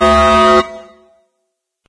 Стандартная сирена средней амплитуды.